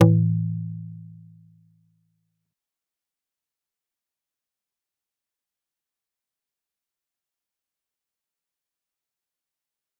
G_Kalimba-A2-mf.wav